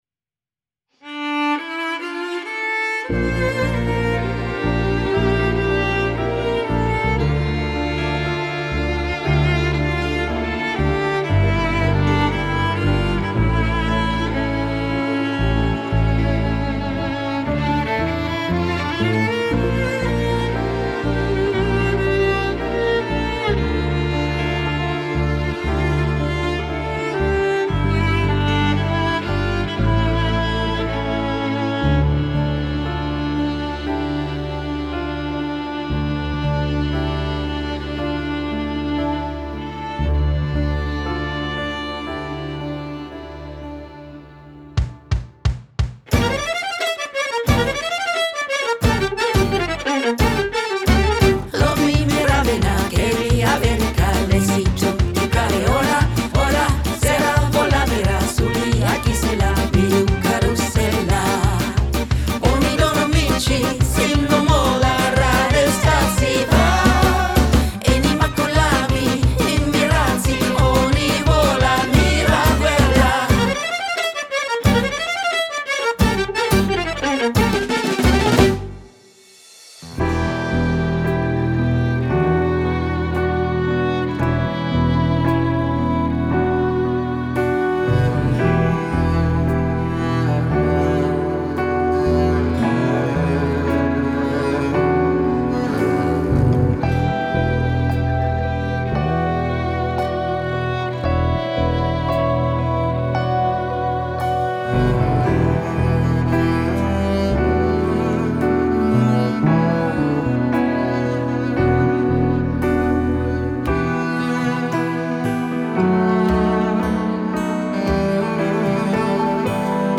Gesang
Violine
Akkordeon
Gitarre
Marimba/Percussion
Kontrabass/Klavier
Audio-Mix